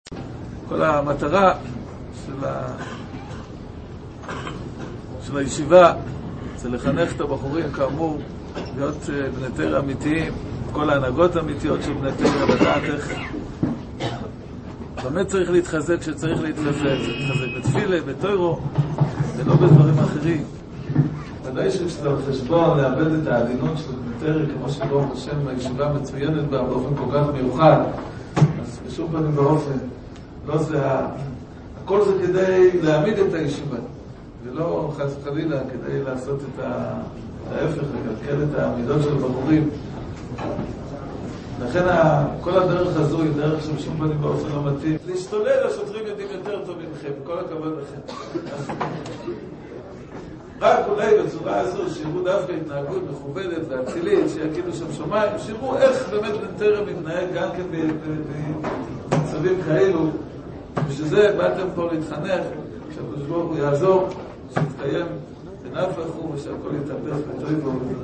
שיחתו
בישיבה ערב הפינוי